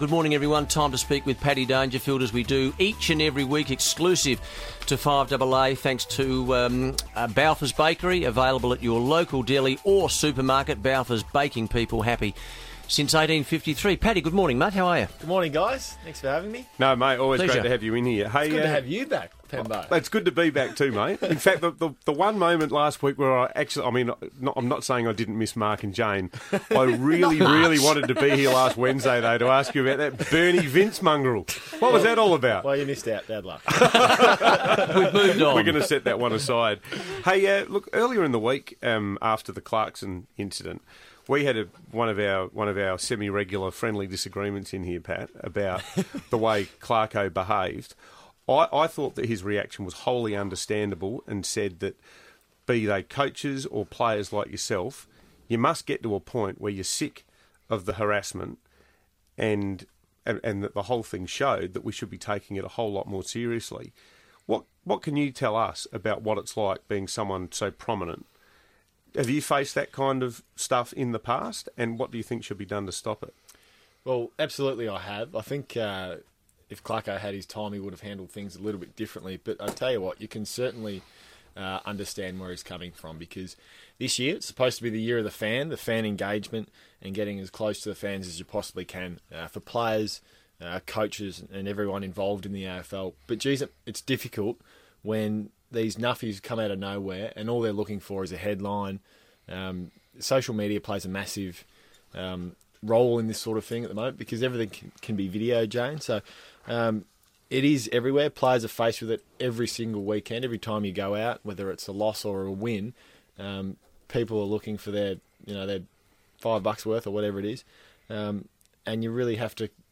Patrick Dangerfield spoke on his regular spot on FIVEaa's breakfast show this week